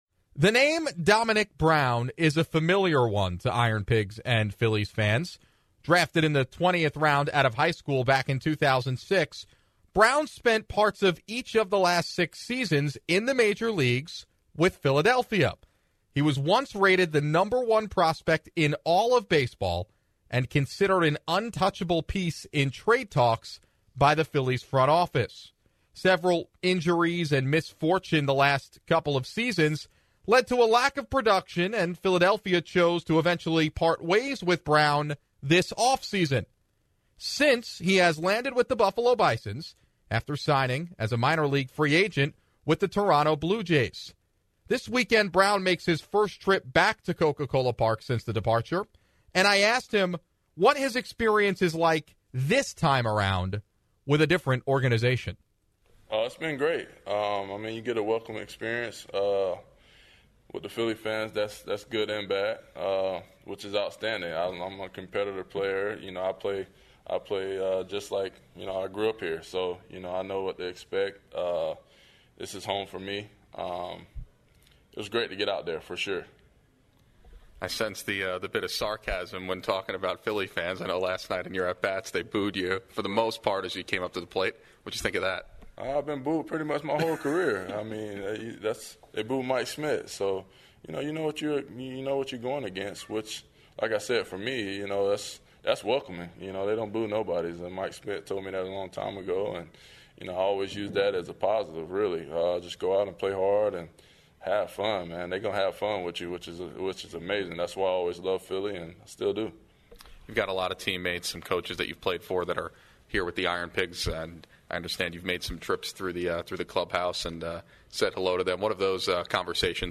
INTERVIEW with former Pigs OF Domonic Brown